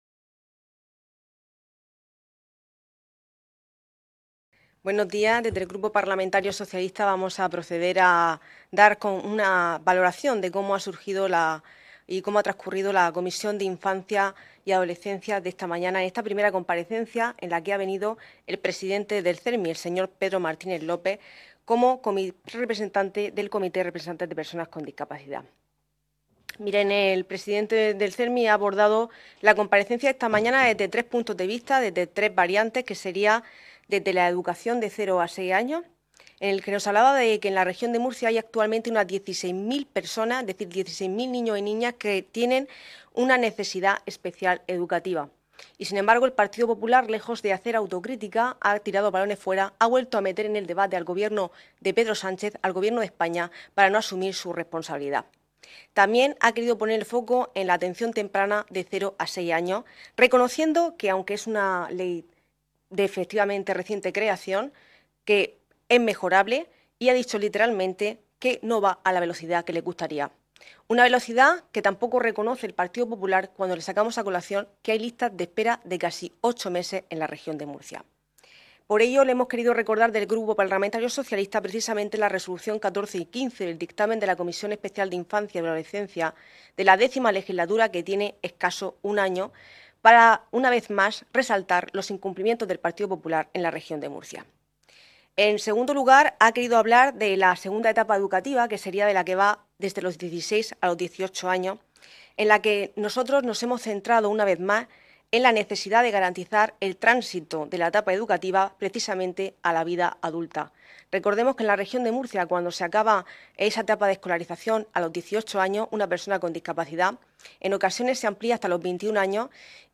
Ruedas de prensa tras la Comisión Especial de Estudio sobre Infancia y Adolescencia